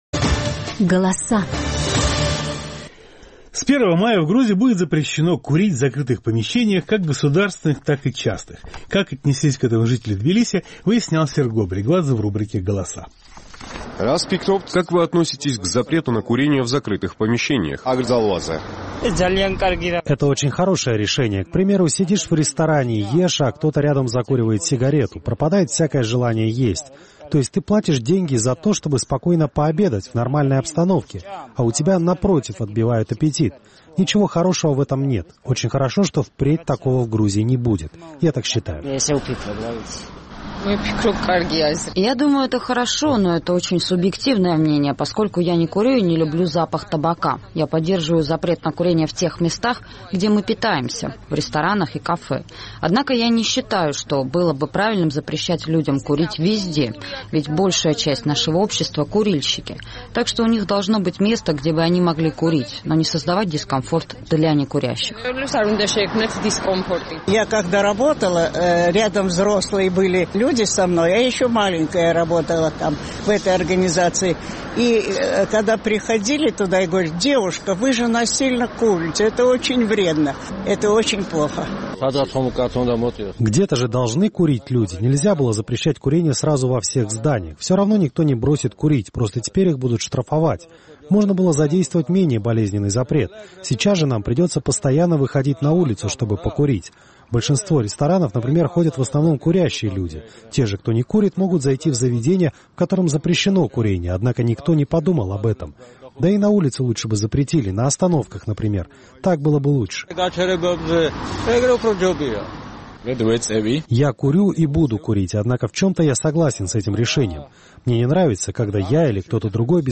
С первого мая в Грузии будет задействован запрет на курение табачных изделий в закрытых помещениях, как государственных, так и частных. Наш тбилисский корреспондент узнавал, как отнеслись к этому жители грузинской столицы.